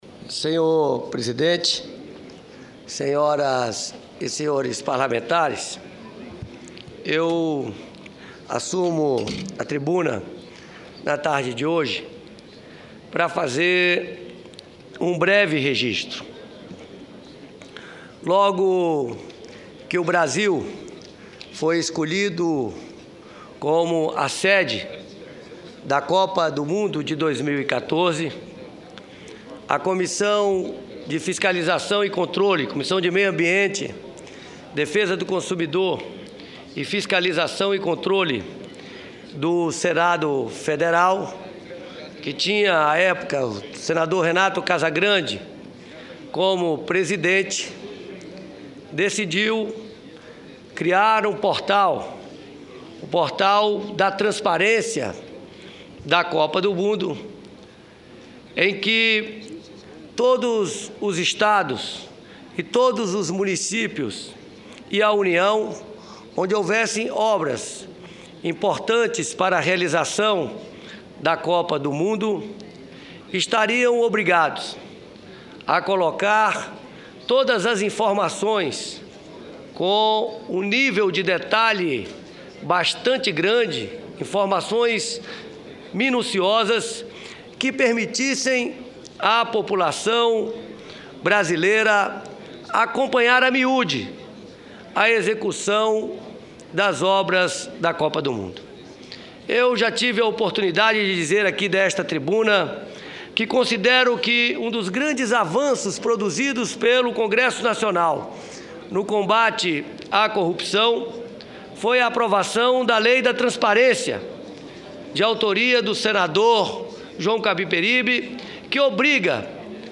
O senador Rodrigo Rollemberg (PSB-DF) comunicou que o portal, disponibilizado pela Comissão de Meio Ambiente, Defesa do Consumidor, Fiscalização e Controle  já está ativo e acessível para a população que tem interesse em acompanhar os gastos dos estados e municípios com a Copa do Mundo de 2014. Disse também que o objetivo do site é fornecer transparência ao processo de gastos com a Copa e ao mesmo tempo ampliar os mecanismos de controle social sobre a aplicação dos recursos públicos.